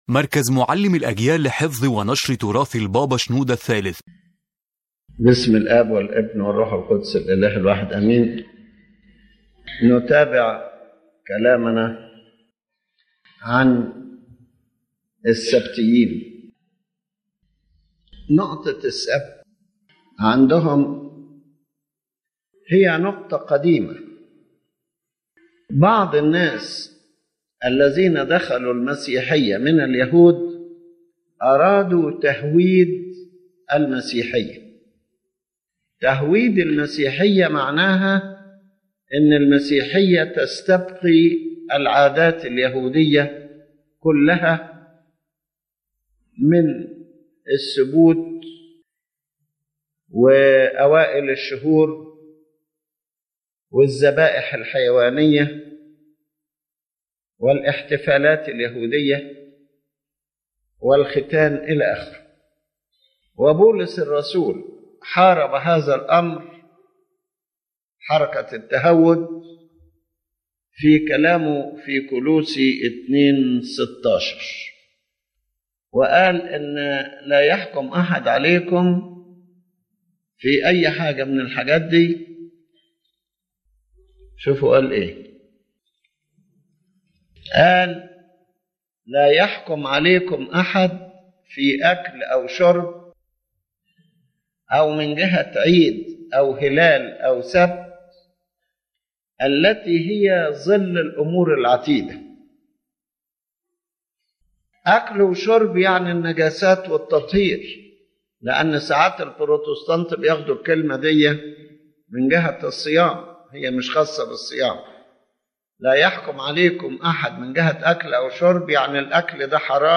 The lecture discusses the Sabbatarians and the Adventists and shows that their roots are Judaizing, meaning an attempt to retain Jewish customs literally (feasts, food and drink, new moons, sacrifices, the Sabbath). The father notes that the Apostle Paul analyzed these issues and considered some of them “shadows” that people should not be judged on (Colossians 2:16), and he explains how Christ transferred the meaning of the Sabbath to a spiritual dimension and showed that doing good is permissible even on the Sabbath to confront Jewish literalism.